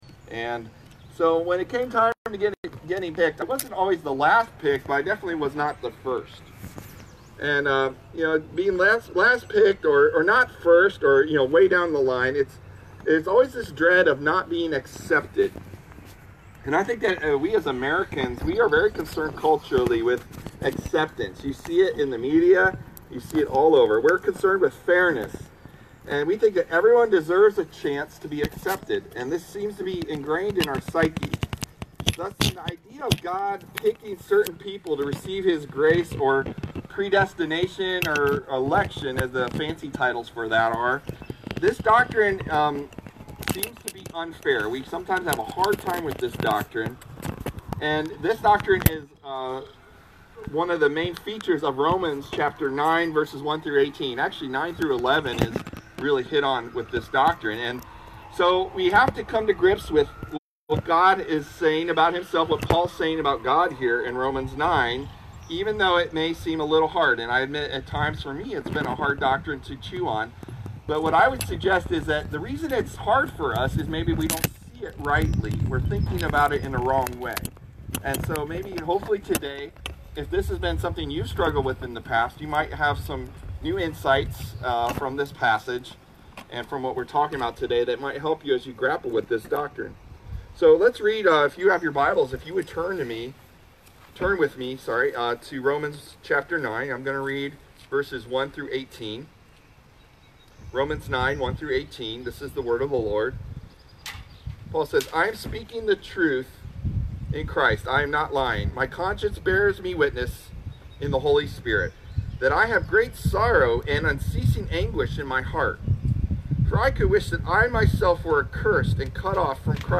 Our video message was recorded on Facebook Live.